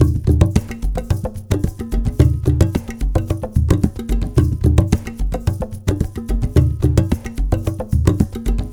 APH ETHNO1-L.wav